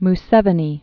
(m-sĕvə-nē), Yoweri Kaguta Born 1944?